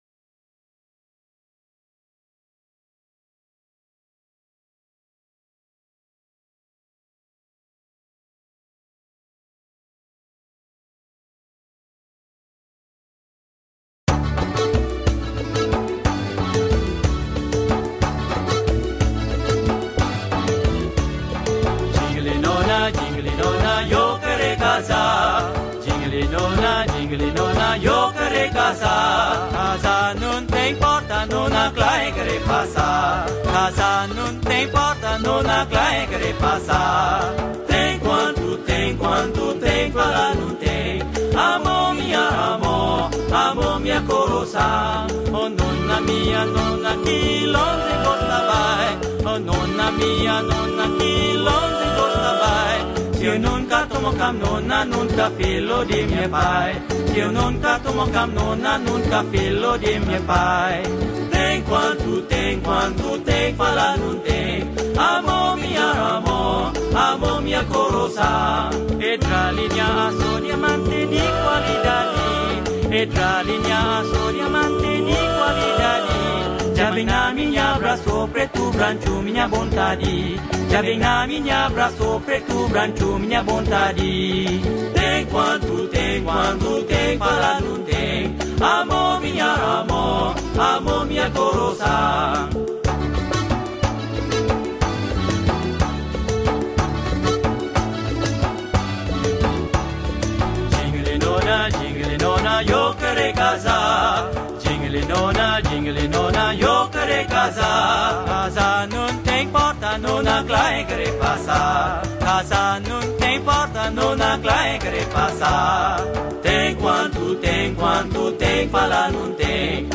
Portuguese Old Folk Song , Skor Angklung